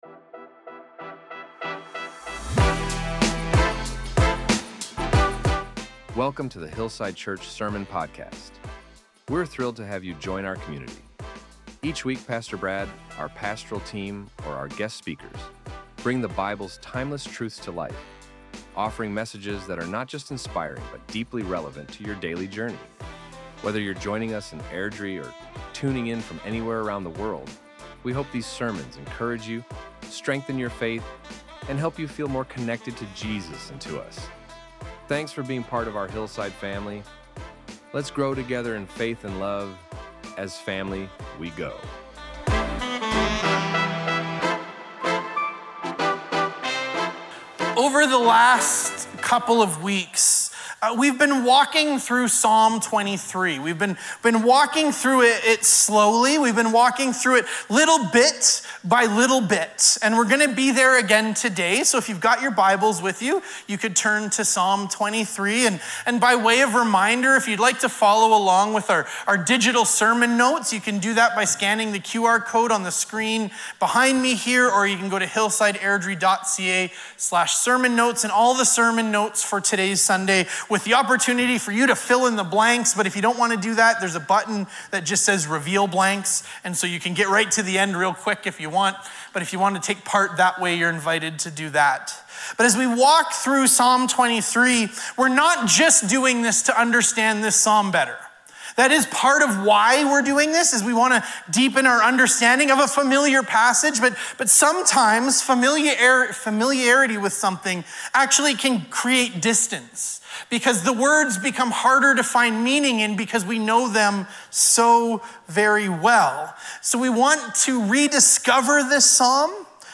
This sermon invited us to release the pressure to make ourselves right and instead trust the One who already has.